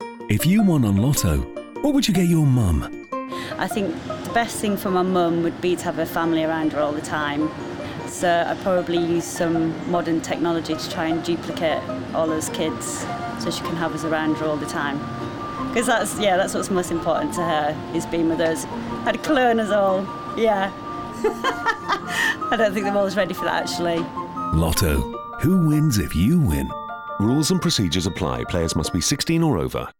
Her voice has a warm, welcoming and trustworthy tone which can be easily adapted to suit a range of genres.
Commercial Showreel Asda Booking Dot Com Heinz Nationwide National Lottery Be The Difference Poem